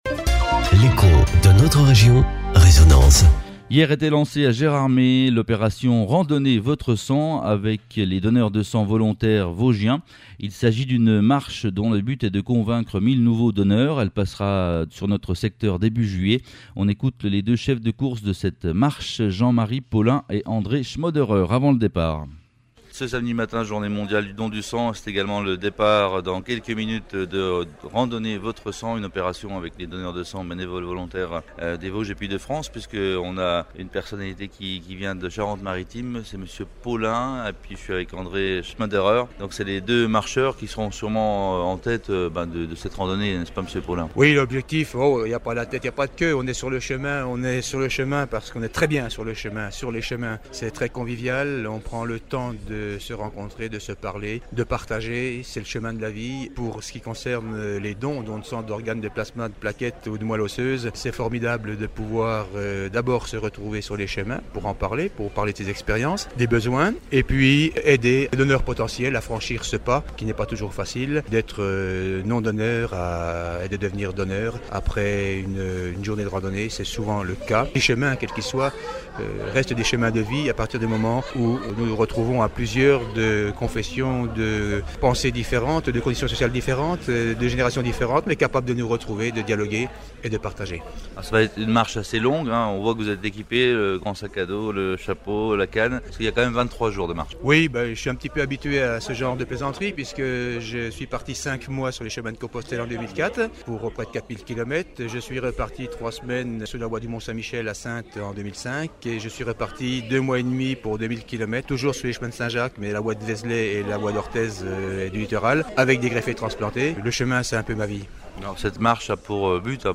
Reportage de France 3 Reportage de Résonance F.M. (Soyez patient, le fichier étant lourd, il va venir dans un instant à vos oreilles après votre clic !)